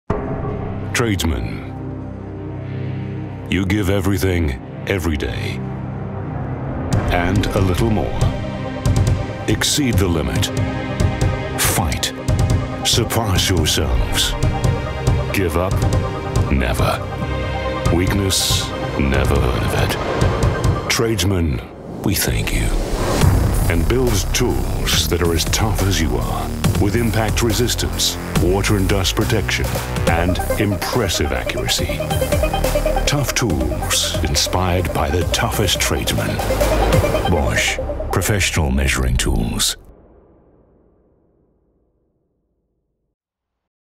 Not American but not quite British either, I have a neutral accent that has been well received in continental Europe precisely because it is neither.
English - Transatlantic
Middle Aged